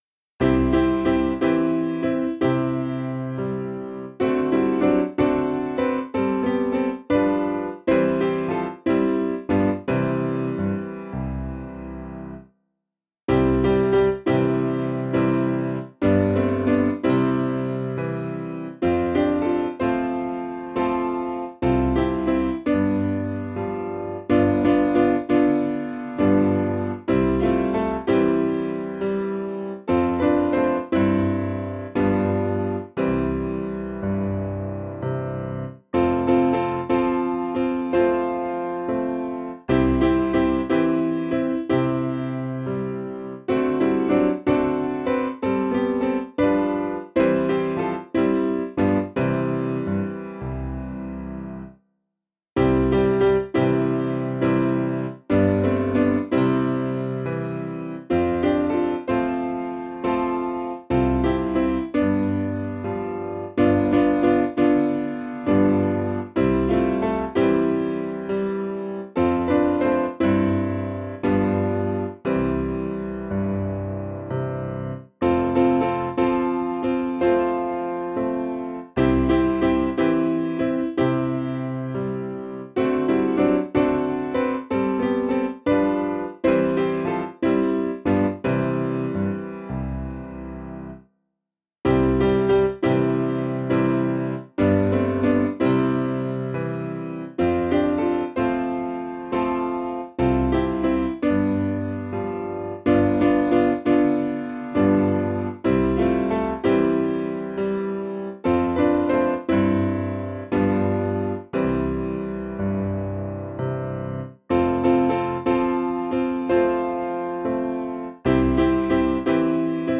Information about the hymn tune GABRIEL (Gabriel).